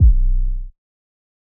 Since Way Back Kick 1.wav